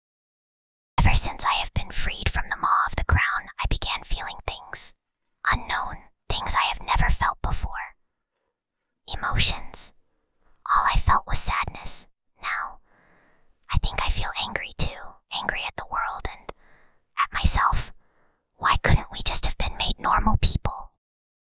Whispering_Girl_21.mp3